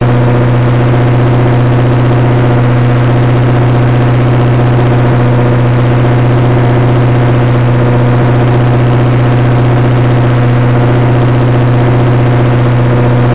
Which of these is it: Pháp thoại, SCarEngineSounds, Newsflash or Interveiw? SCarEngineSounds